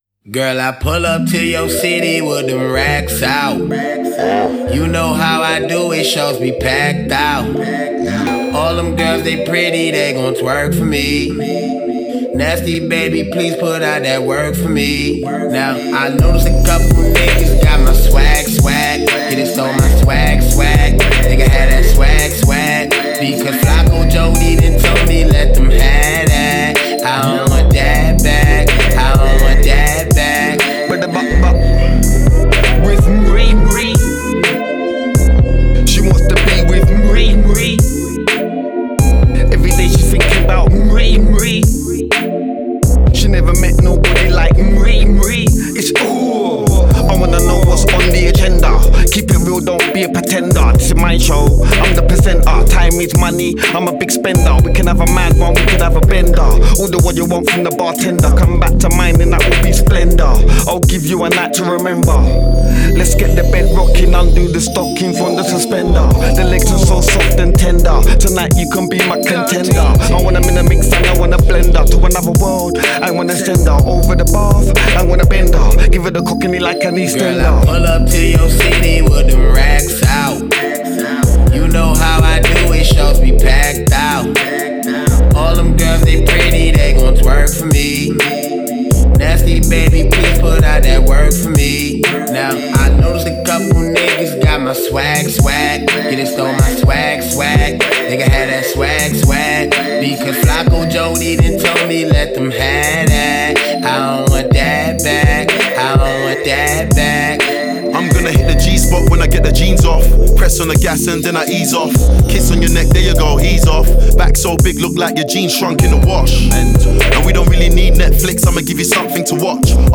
Жанр: grime